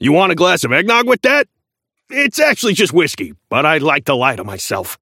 Shopkeeper voice line - You want a glass of eggnog with that?